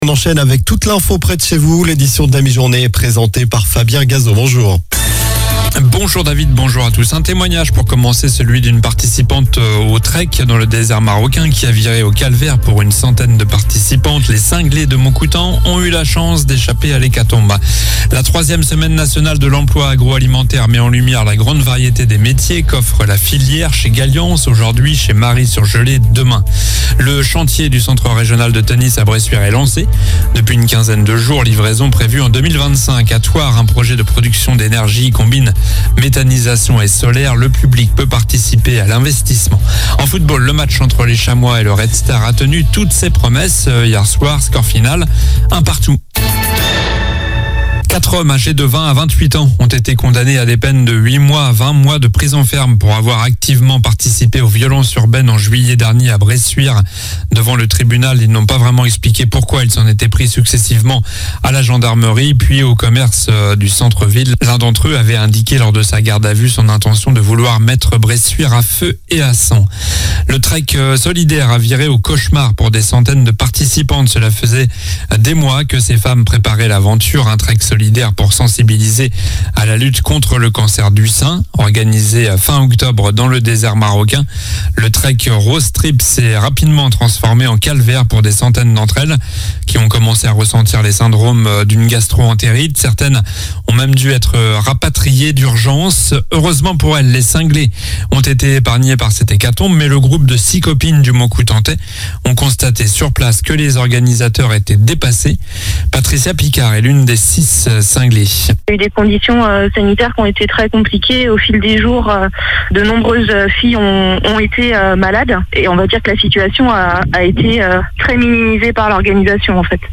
Journal du mardi 07 novembre (midi)